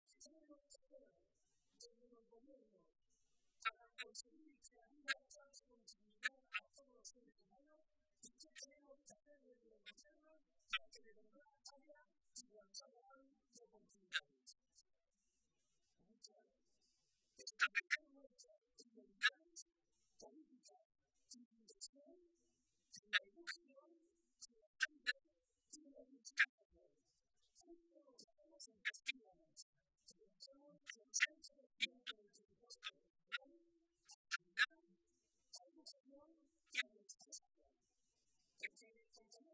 El presidente José María Barreda asistía este domingo a la tradicional comida de Navidad del PSOE de Toledo, en la que participaron unos 1.600 militantes y simpatizantes de toda la provincia.